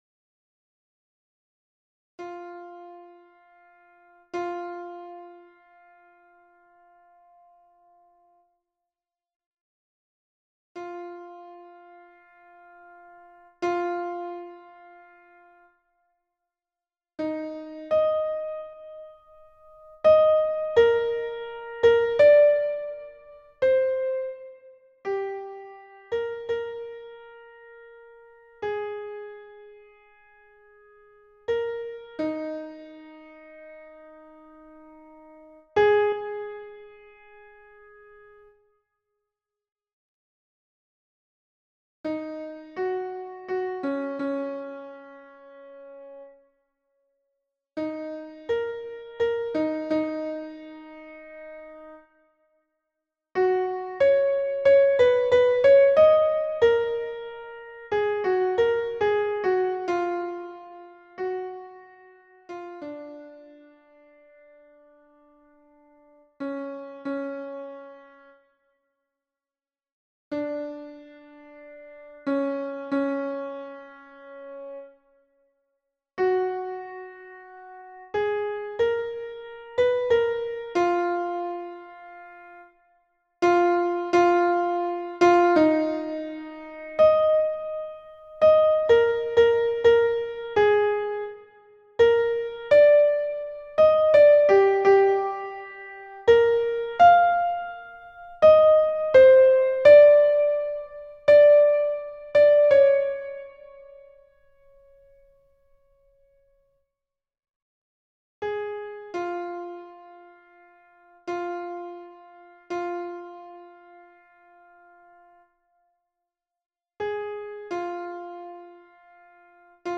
MP3 version piano
Mezzo